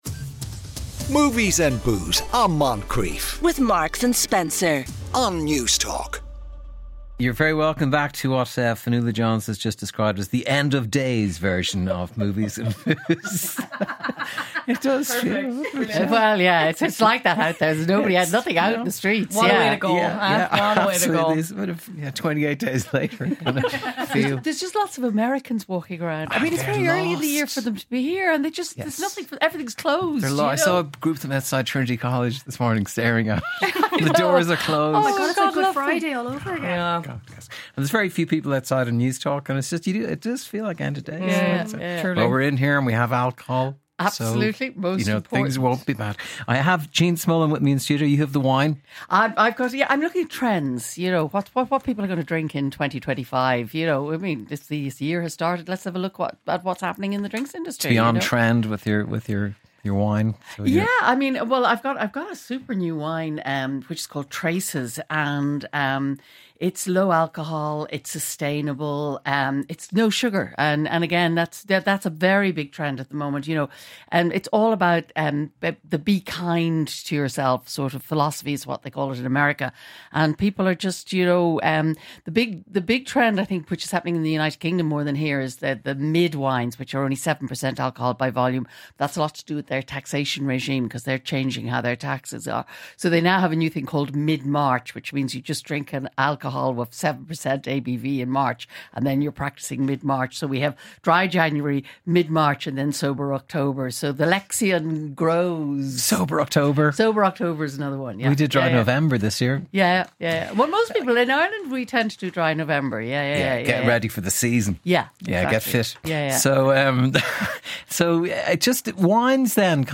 Movies and Booze LIVE from the Dún Laoghaire National Maritime Museum.
Each week in a relaxed and casual environment the three chat about movies over some beer or wine just like being in a pub or at a dinner party!